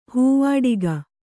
♪ hūvāḍiga